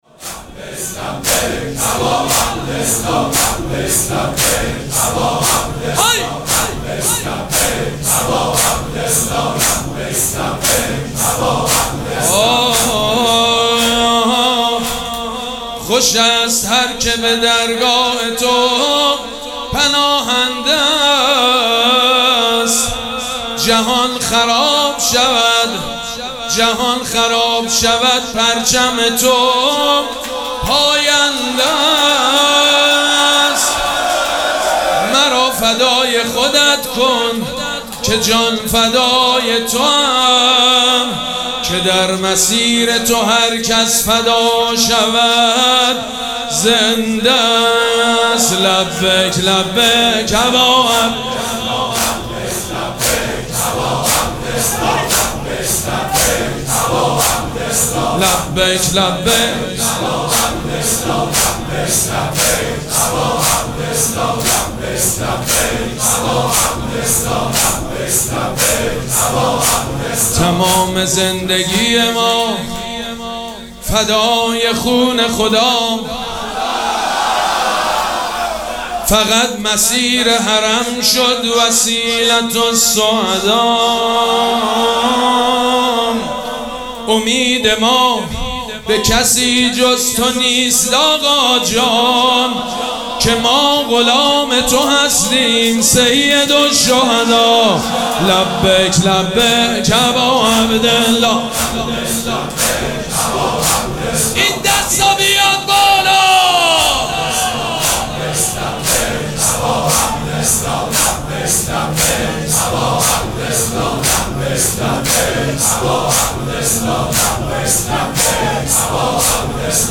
مراسم عزاداری شب چهارم محرم الحرام ۱۴۴۷
مداح
حاج سید مجید بنی فاطمه